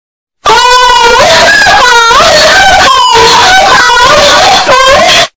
Gemido
Um super gemidos zuar seus amigos